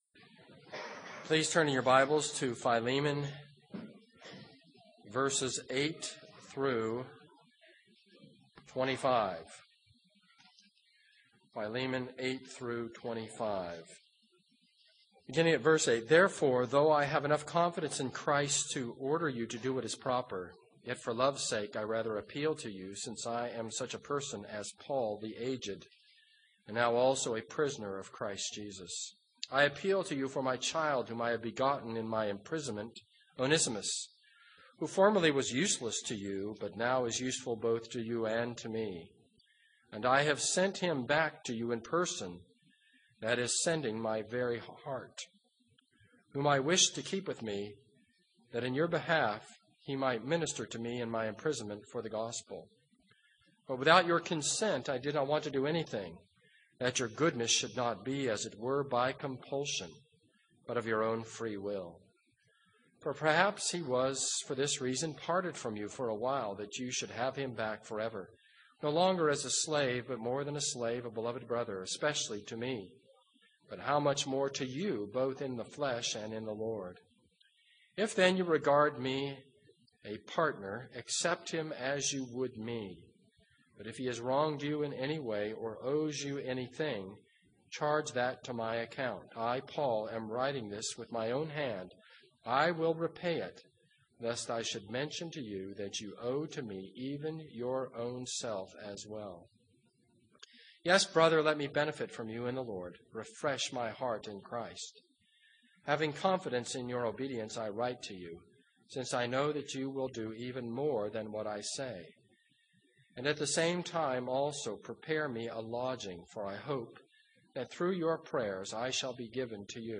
This is a sermon on Philemon 1:8-25.